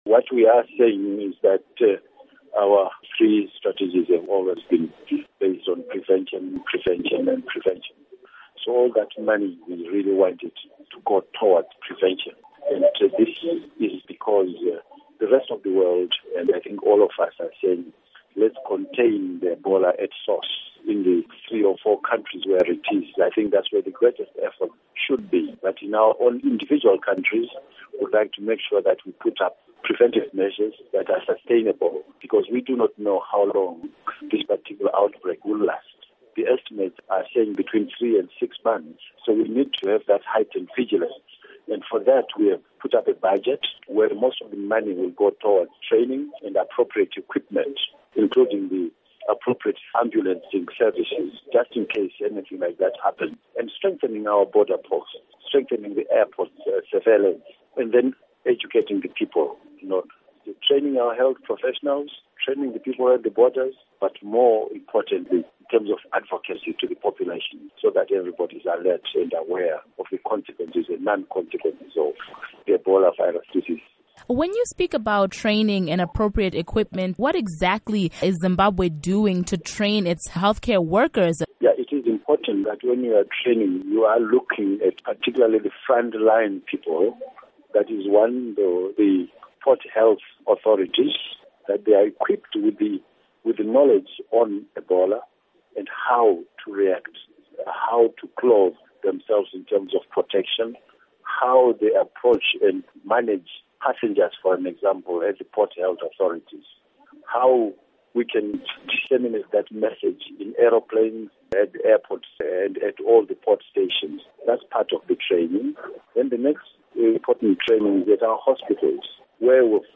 Interview with Dr. David Parirenyatwa